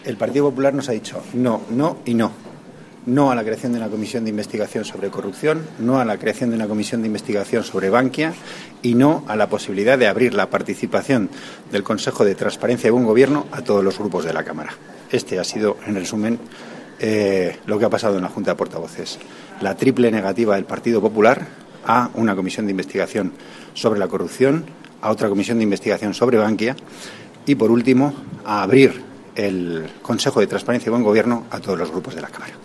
Declaraciones de Antonio Hernando al termino de la Juanta de Portavoces del 9/12/14